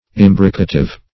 Imbricative \Im"bri*ca*tive\, a.